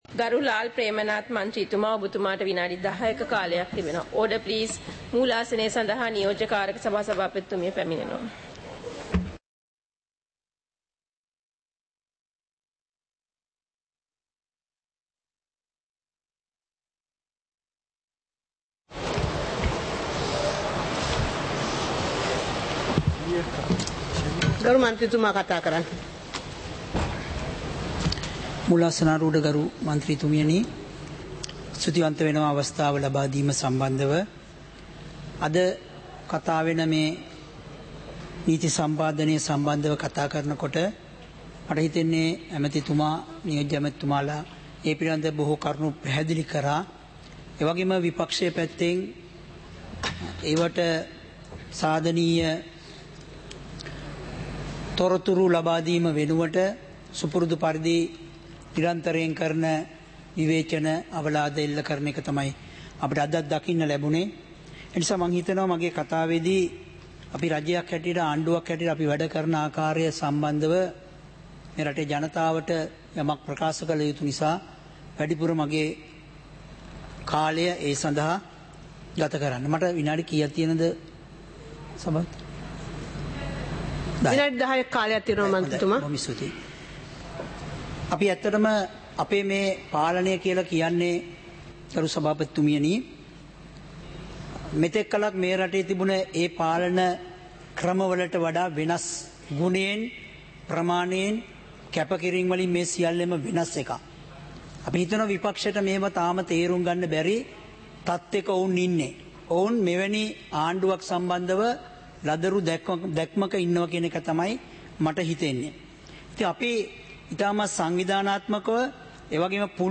இலங்கை பாராளுமன்றம் - சபை நடவடிக்கைமுறை (2026-02-18)